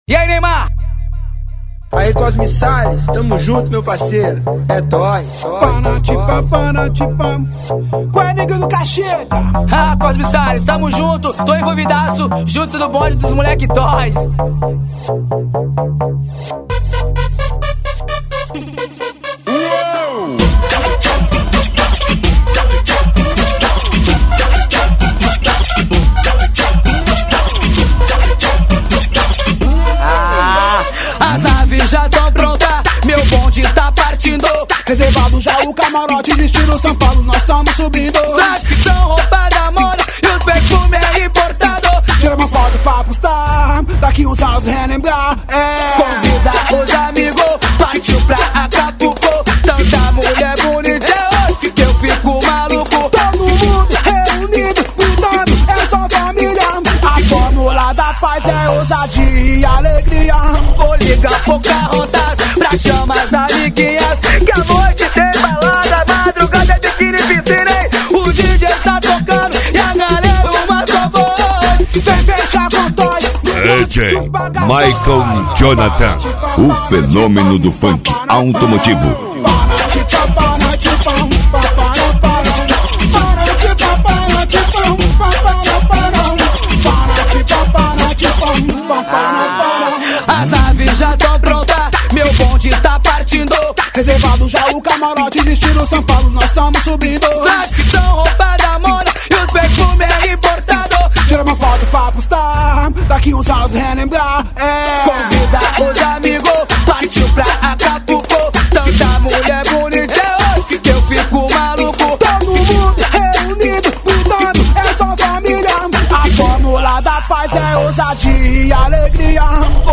اینم خونندگی نیمار :))